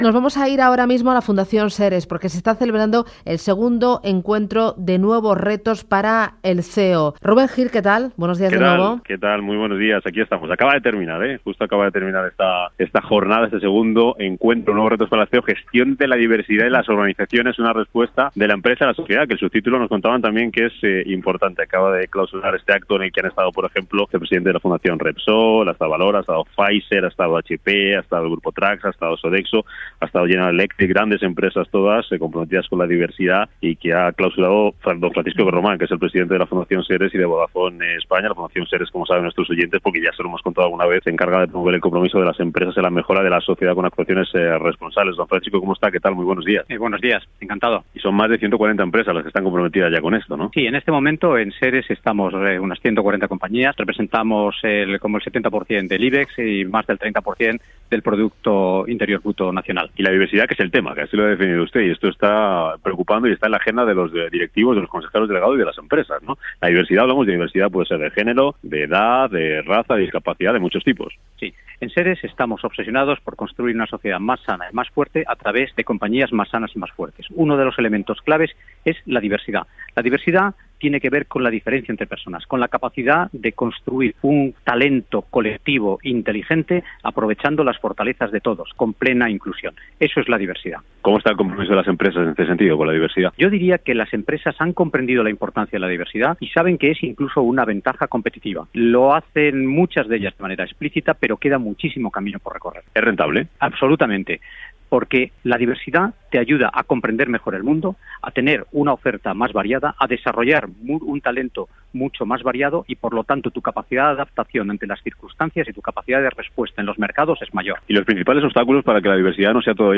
Entrevista Fundación SERES en Capital Intereconomia 1704.mp3